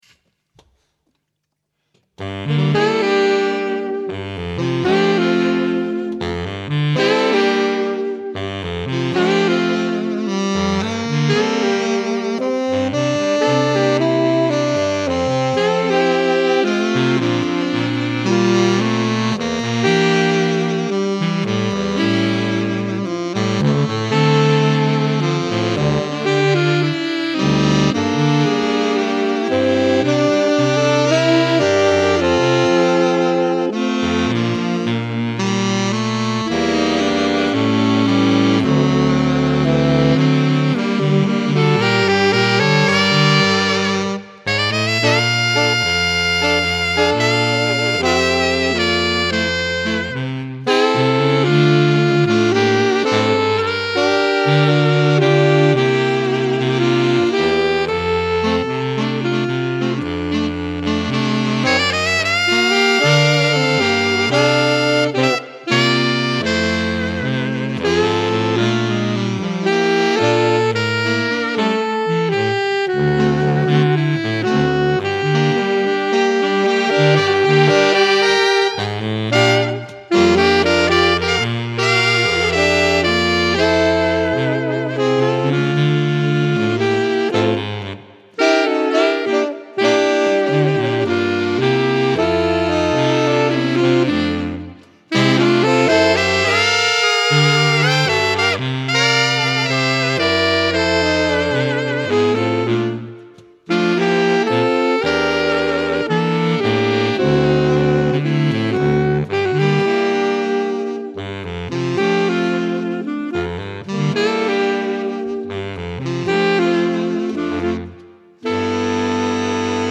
More Saxophone Quartet Music